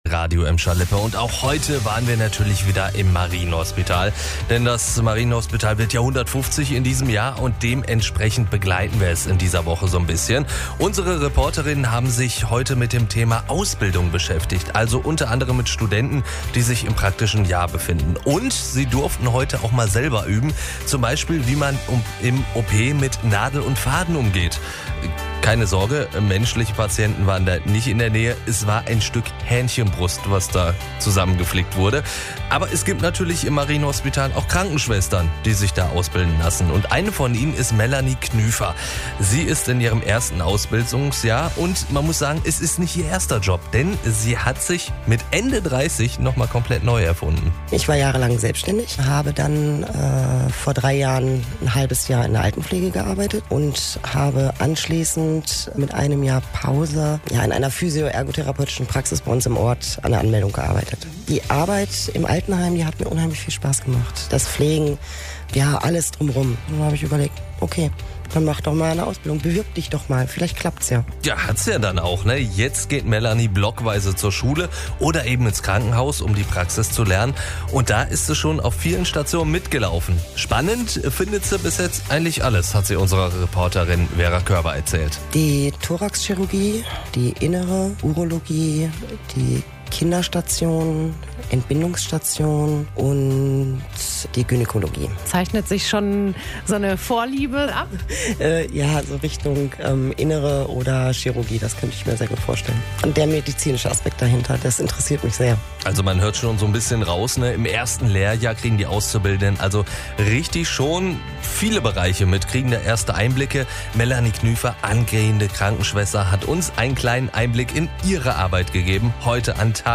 Auch heute waren wir wieder im Marienhospital. Unsere Reporterinnen haben sich mal mit dem Thema Ausbildung beschäftigt, also unter anderem mit Studenten, die sich im praktischen Jahr befinden.